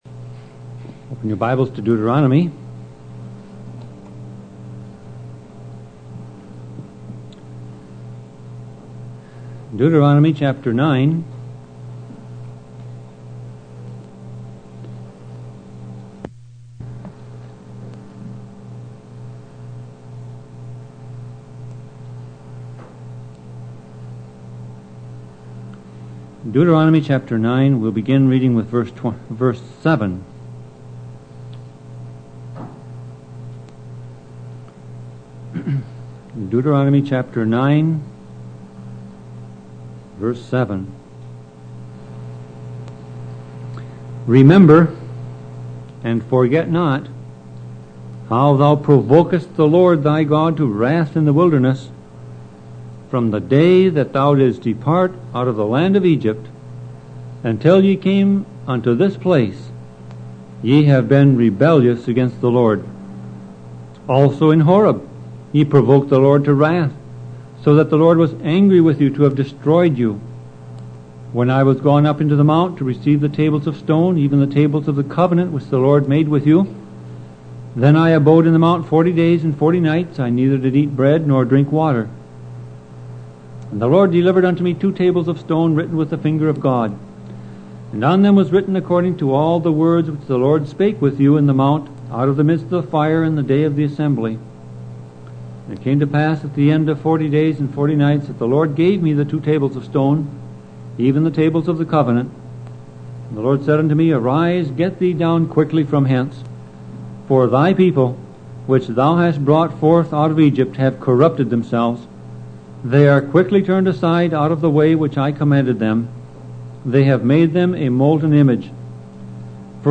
Sermon Audio Passage: Deuteronomy 9:7-24 Service Type